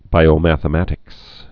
(bīō-măthə-mătĭks)